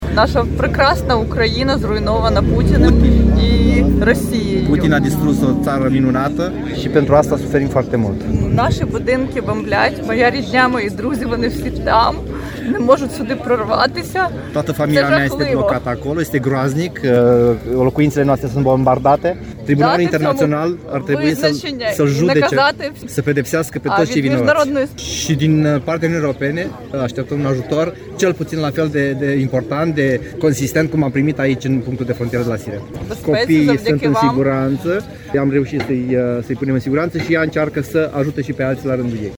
Una dintre refugiate